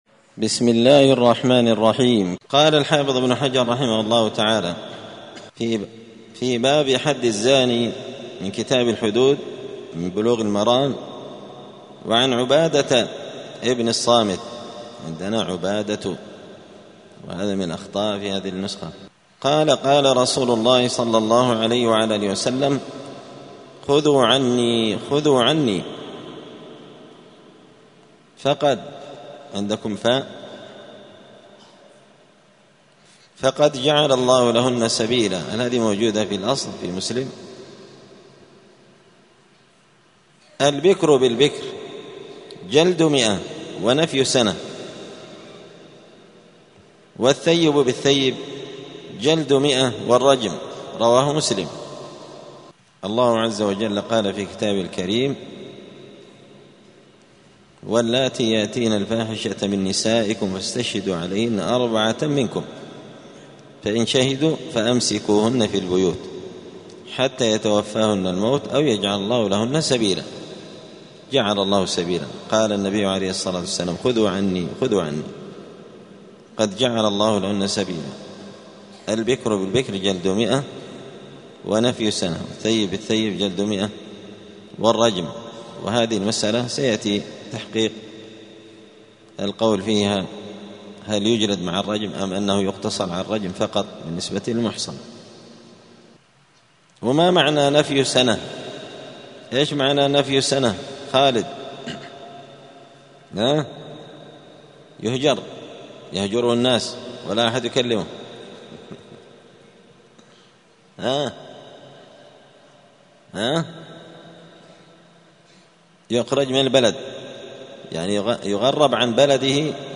*الدرس الثاني (2) {باب جلد الثيب ورجمه}*